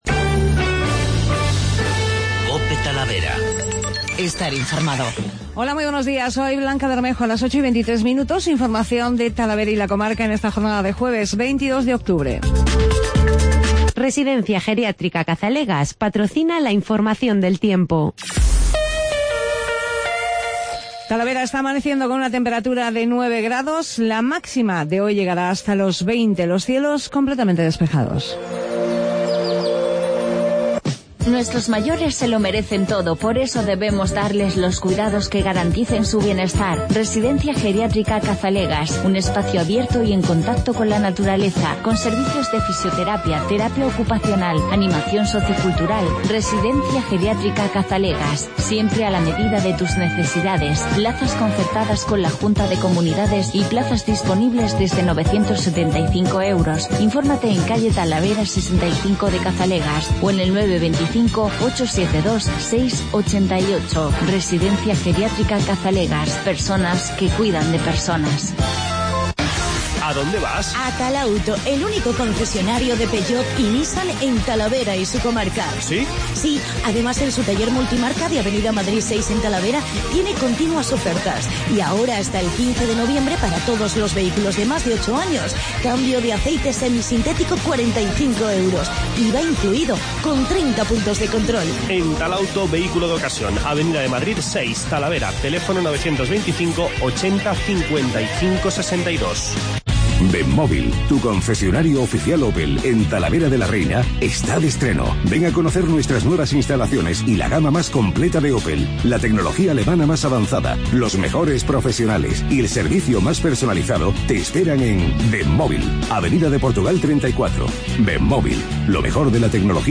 Informativo Matinal